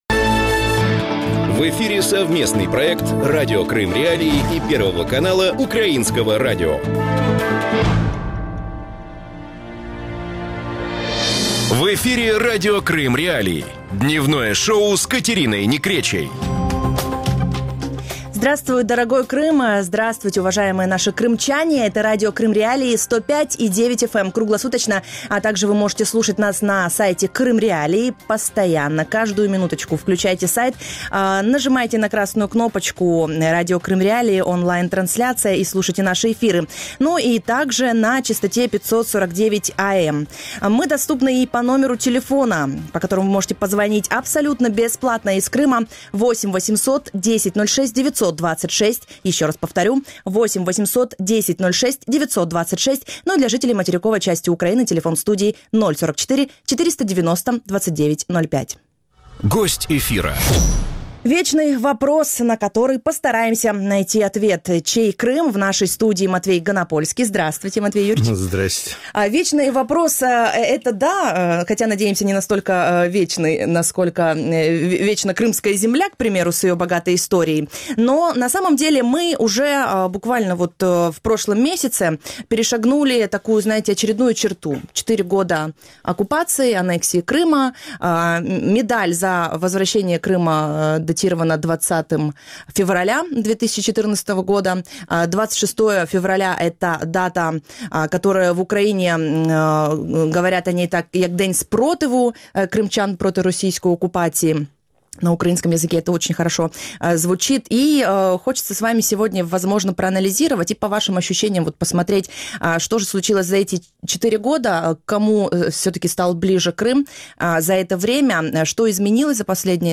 Гость студии – политический публицист и журналист Матвей Ганапольский.